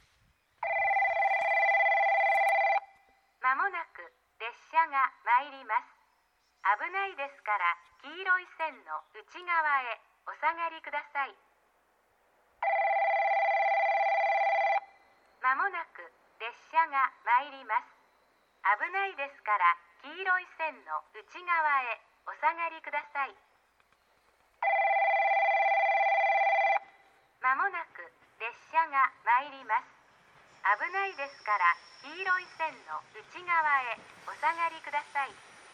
この駅では接近放送が設置されています。
接近放送普通　西都城行き接近放送です。